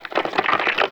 ALIEN_Communication_21_mono.wav